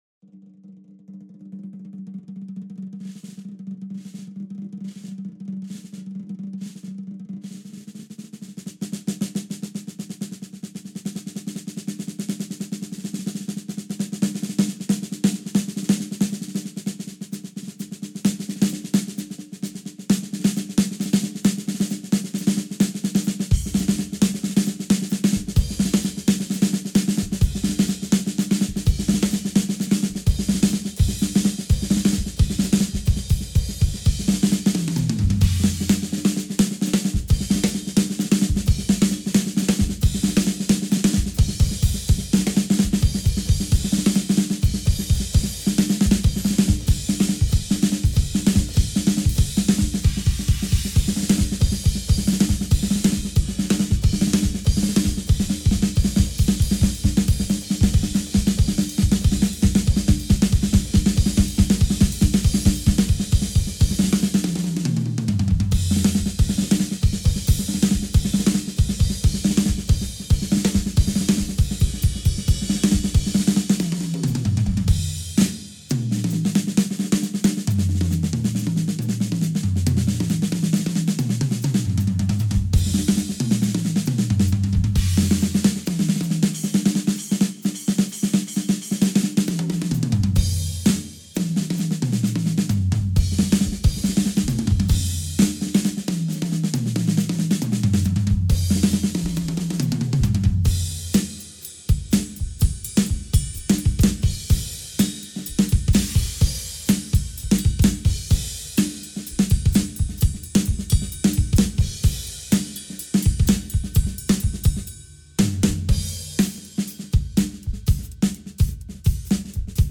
Ein kleiner Mitschnitt, während ich mich warm trommelte: